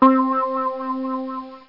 Synth Spring Sound Effect
Download a high-quality synth spring sound effect.
synth-spring.mp3